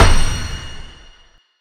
bookBoom.ogg